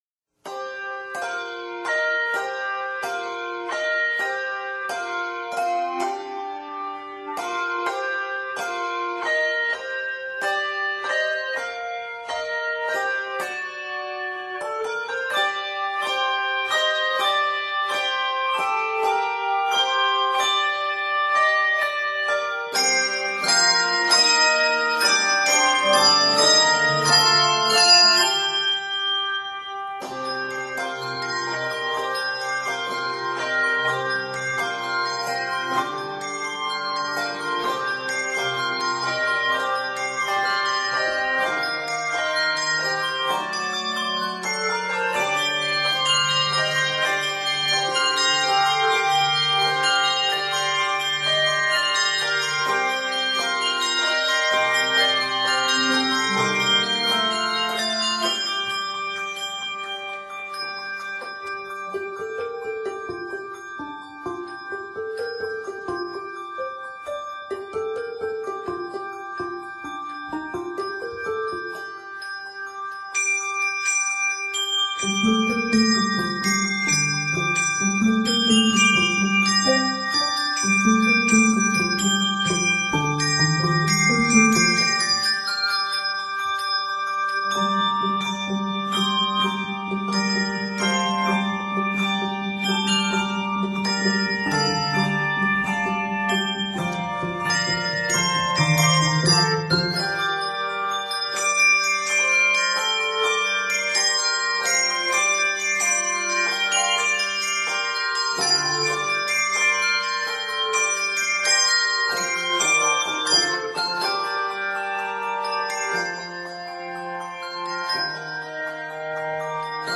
Set in g minor, it is 98 measures.